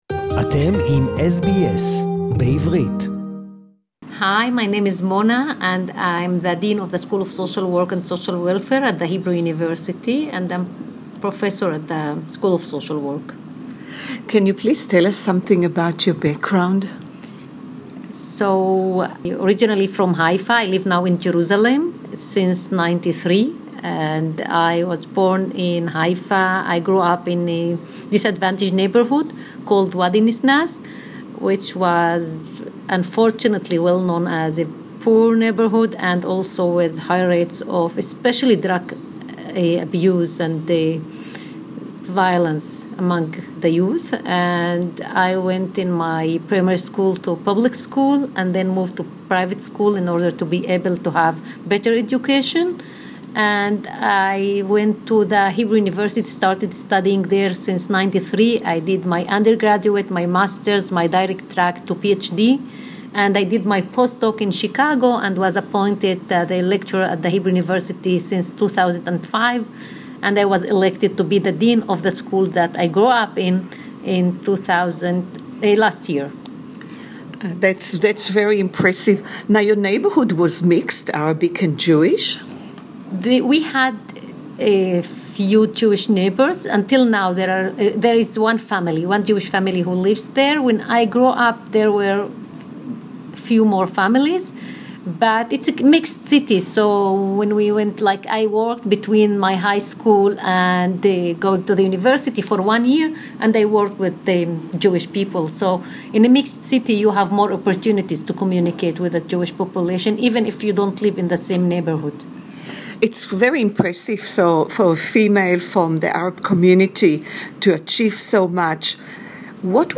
Our interview today is in English